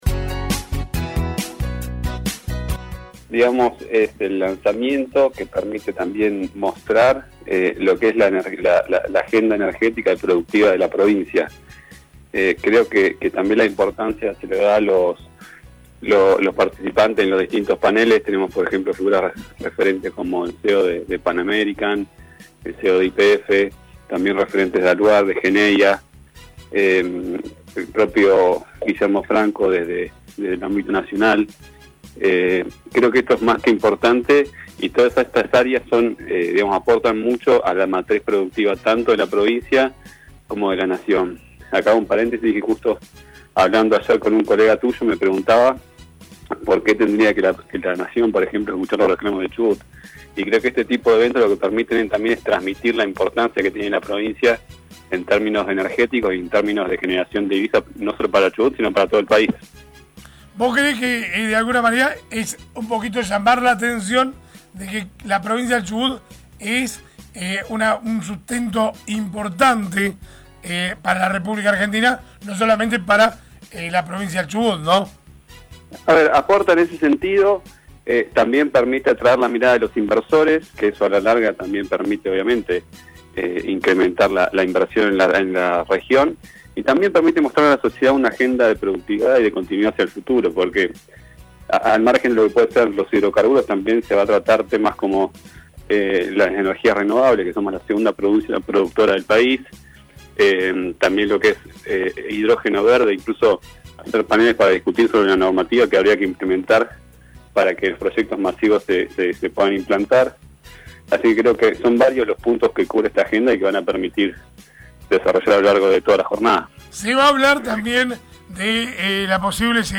Así lo comentó a LA MAÑANA DE HOY el Ministro de Hidrocarburos, Federico Ponce: